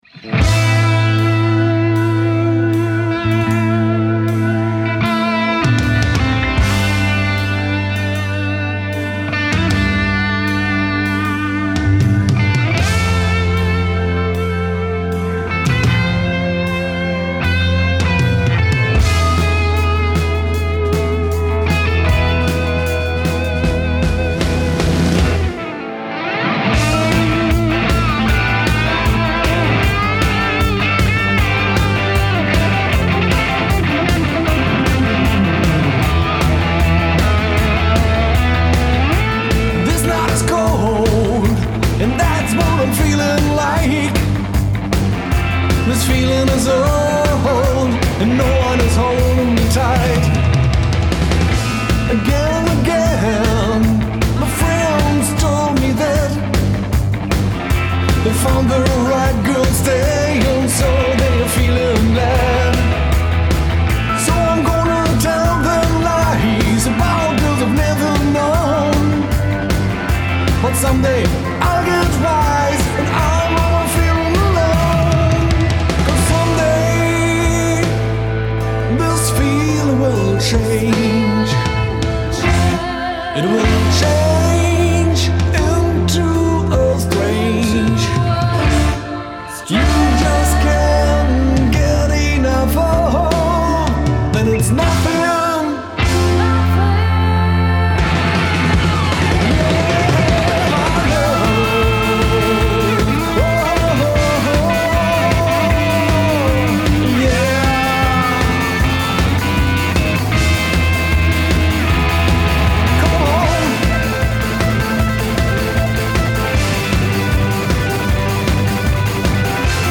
Produktion eines Uptempo 70s-Rocksong.